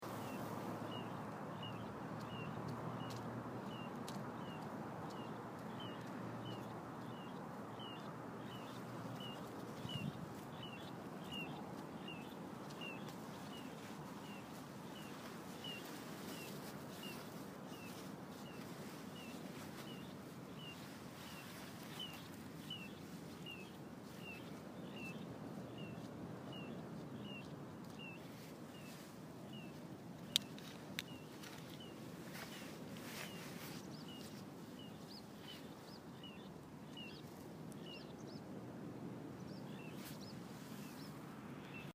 コチドリ
４月の雨の朝。
ピ・ピ・ピという声に巻かれました。コチドリのテリトリーにつっこんでしまったようです（ふだん人通りの多いサイクリングロードなんですけど）。
コチドリ.mp3 私のまわりをグルグル周りながら鳴いています。
コチドリ.mp3